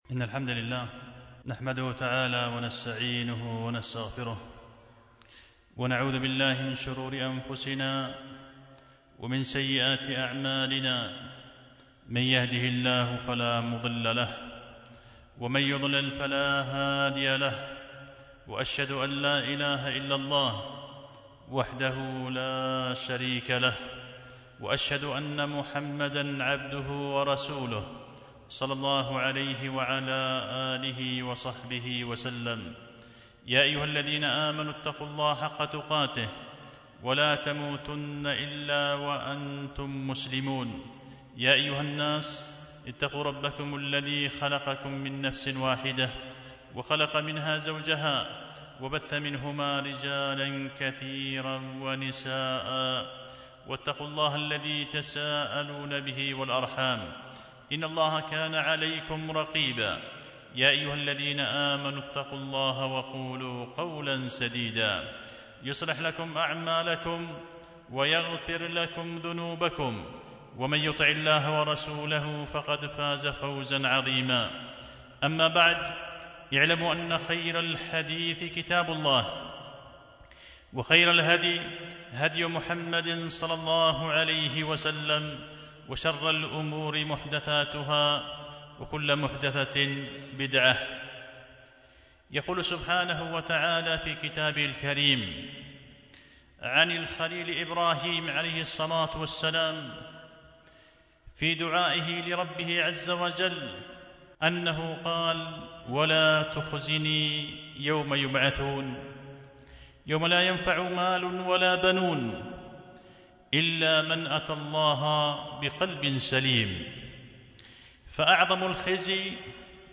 خطبة جمعة بعنوان تبييض السطور في بيان أن أعظم الخزي هو الخزي يوم البعث والنشور